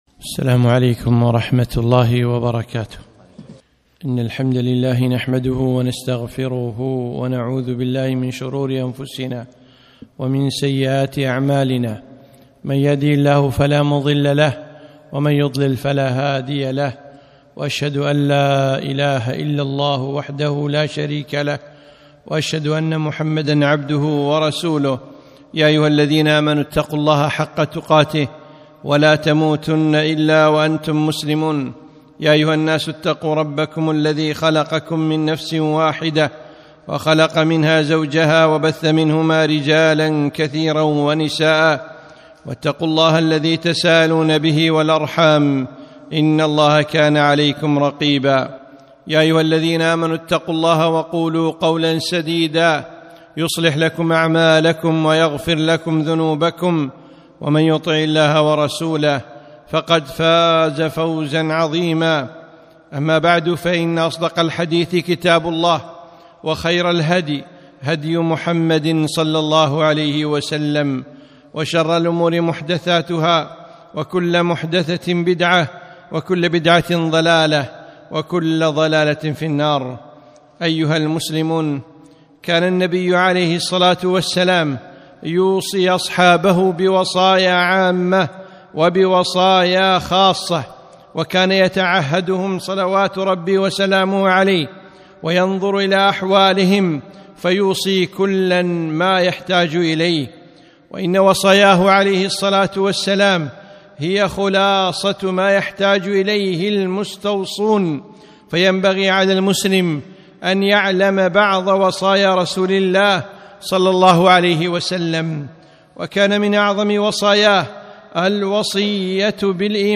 خطبة - من وصايا النبي ﷺ